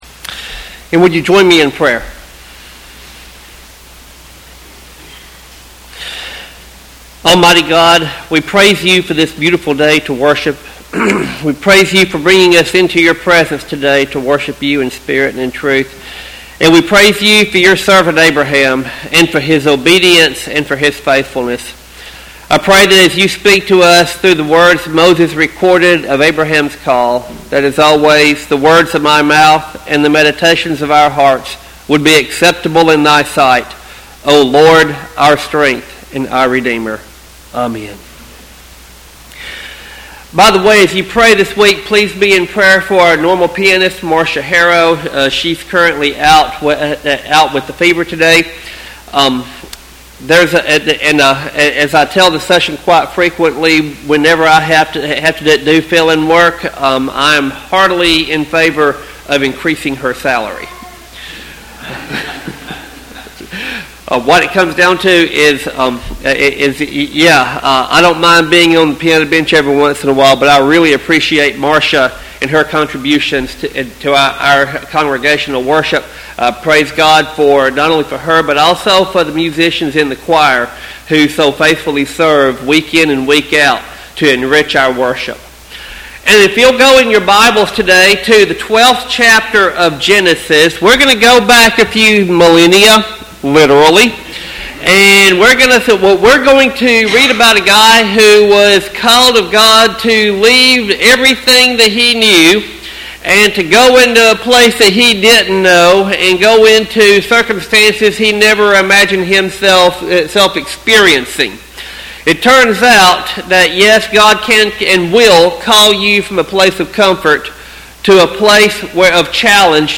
Sermon text: Genesis 12:1-3.